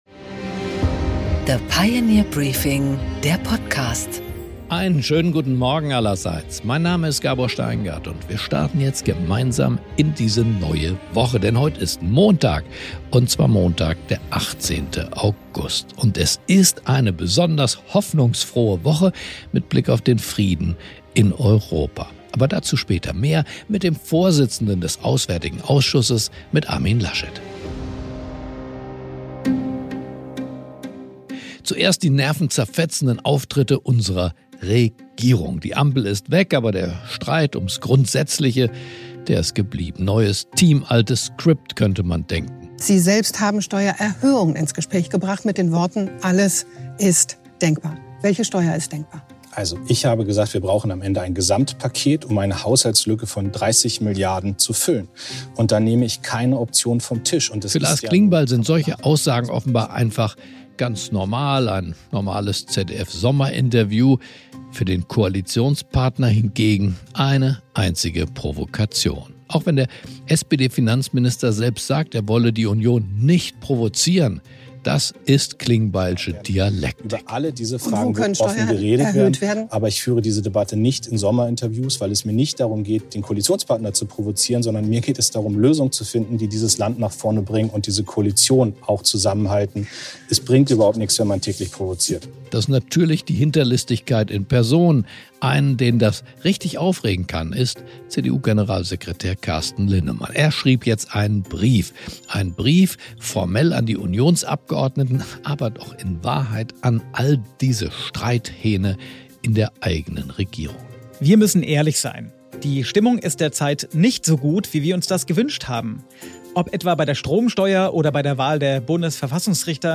Gabor Steingart präsentiert das Pioneer Briefing
Im Gespräch: Armin Laschet, MdB (CDU) und Vorsitzender des Auswärtigen Ausschusses, spricht mit Gabor Steingart über den Besuch von Wolodymyr Selenskyi in Washington, den Druck von Donald Trump – und die Chancen auf einen Waffenstillstand im Ukrainekrieg.